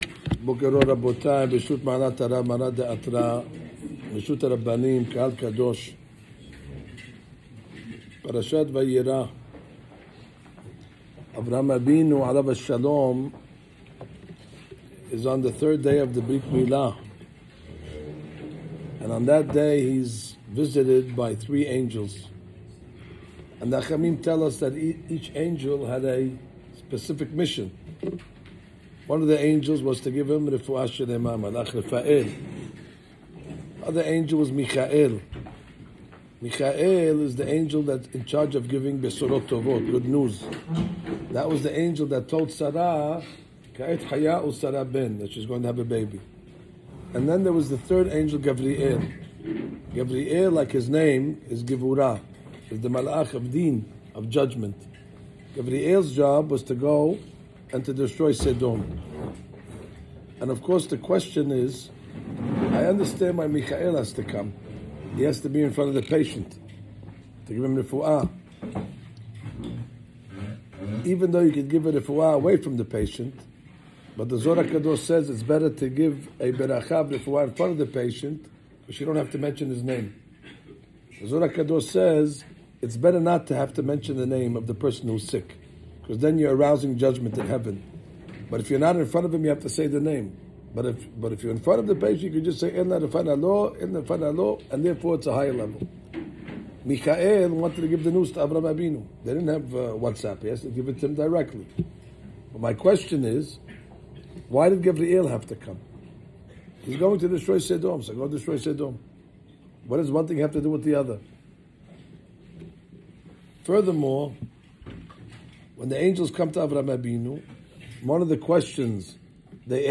Breakfast and the class – Sinagoga Beit Yaacov